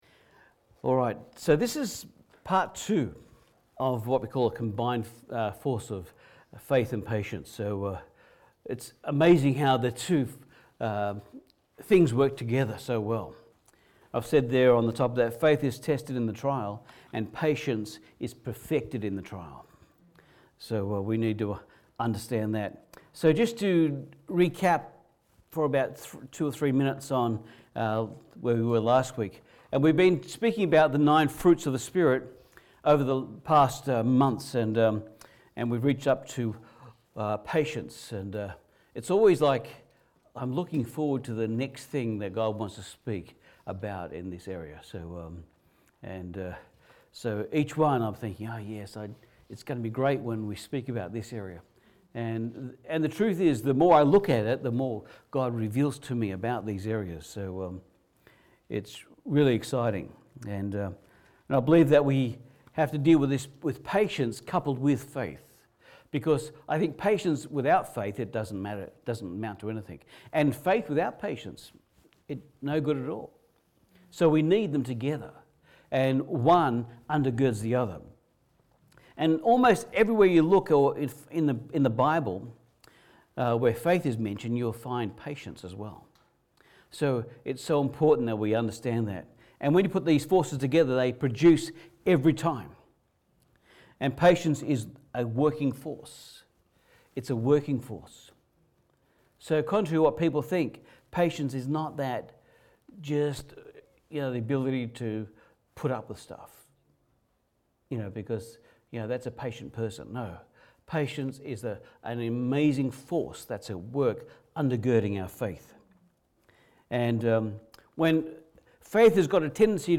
Service Type: Sunday Service